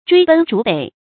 追奔逐北 注音： ㄓㄨㄟ ㄅㄣ ㄓㄨˊ ㄅㄟˇ 讀音讀法： 意思解釋： 追擊敗走的敵軍。